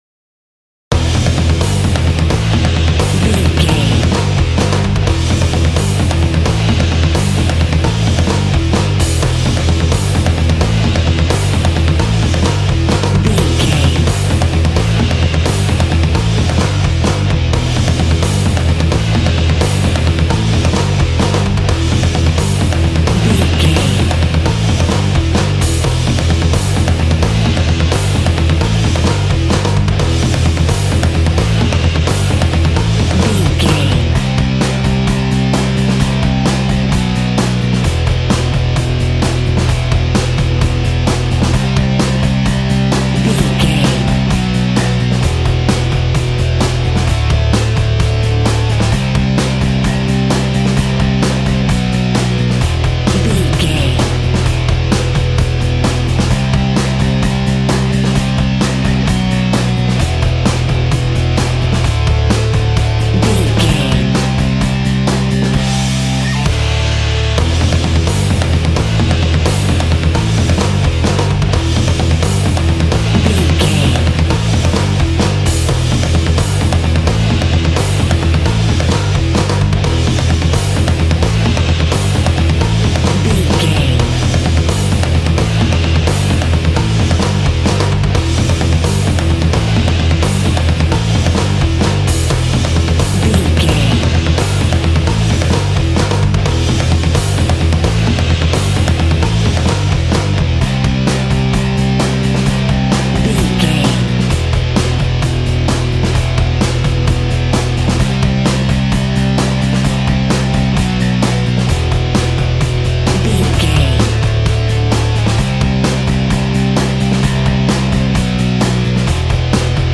Aeolian/Minor
electric guitar
bass guitar